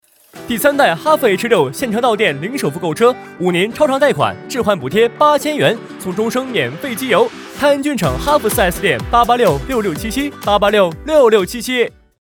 C男152号 | 声腾文化传媒
【广告】青春阳光.mp3